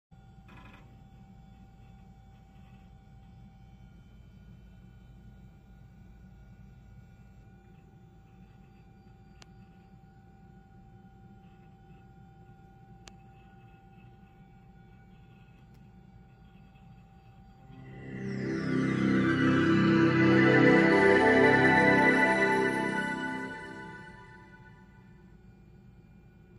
Sounds Of The 90's (Windows sound effects free download
Sounds Of The 90's (Windows 98) Startup Sound